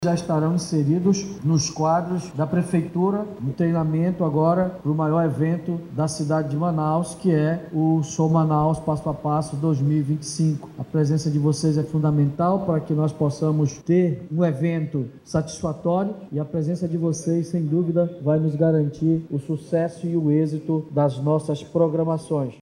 Ainda segundo, o chefe do Executivo Municipal, os 138 guardas vão atuar, de forma prática, no evento cultural “Sou Manaus Passo a Paço 2025”, que será realizado no Centro Histórico da cidade, nos dias 5, 6 e 7 de setembro.